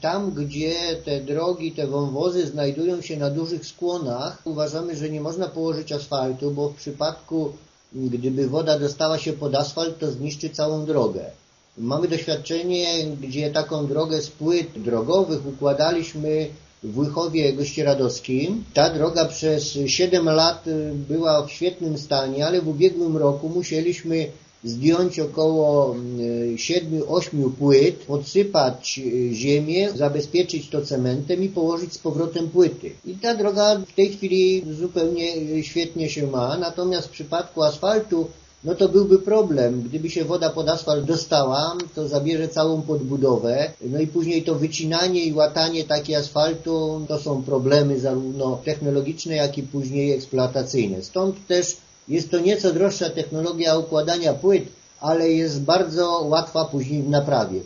Wójt gminy Trzydnik wyjaśnia, że nie każda droga położona w wąwozie może być wykonana z asfaltu. Chociaż użycie ażurowych płyt jest droższe, to jednak, jego zdaniem, bardziej praktyczne: